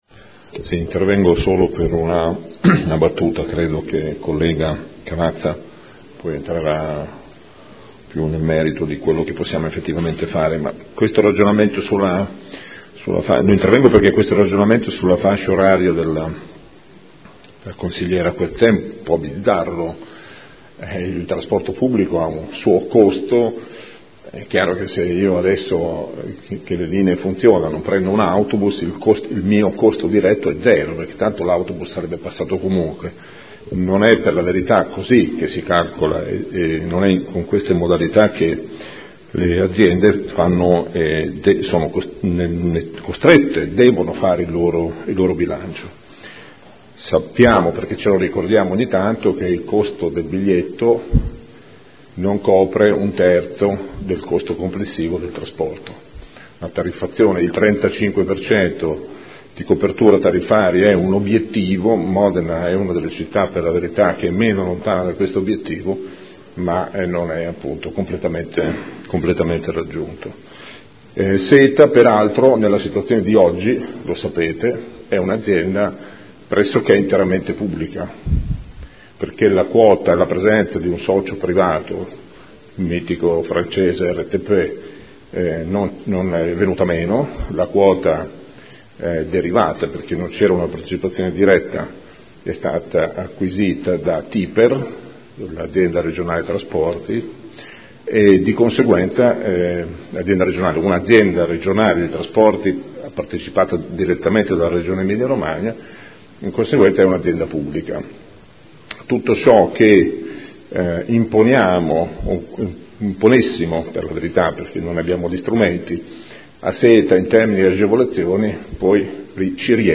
Seduta del 09/02/2015. Dibattito sugli ordini del giorno inerenti il trasporto scolastico gratuito o agevolato